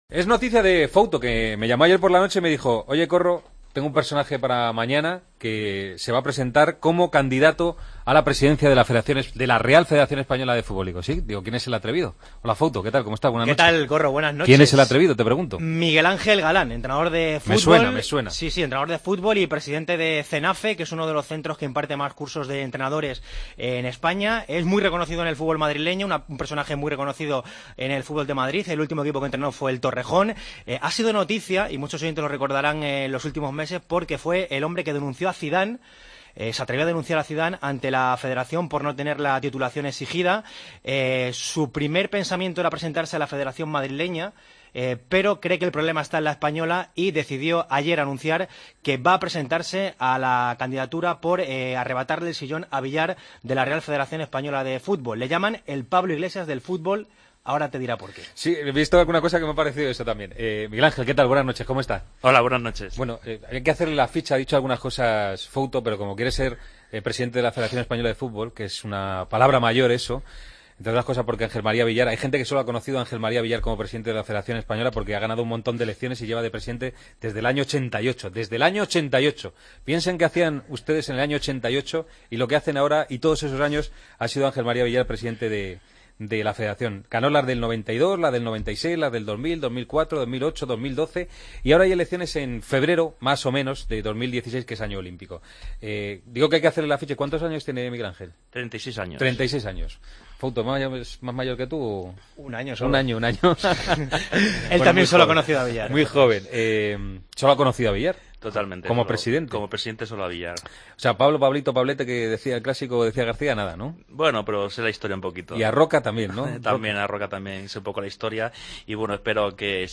El Partidazo de COPE Entrevista